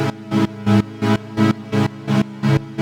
Index of /musicradar/sidechained-samples/170bpm